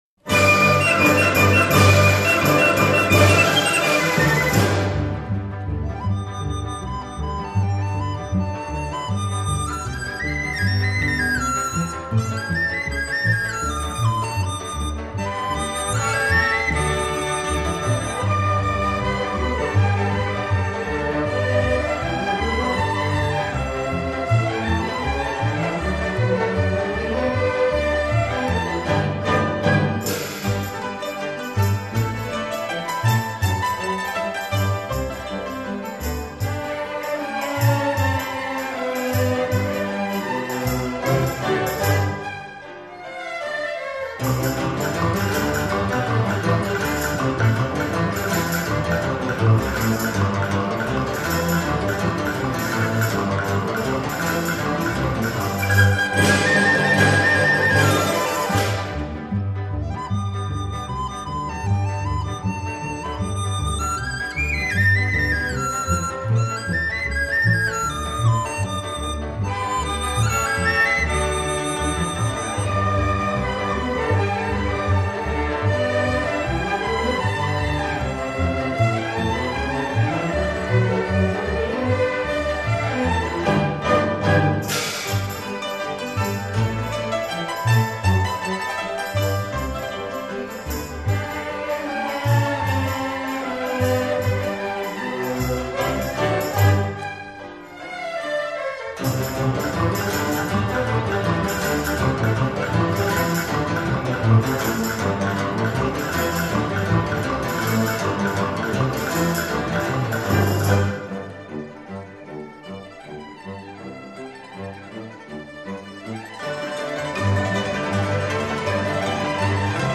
器乐合奏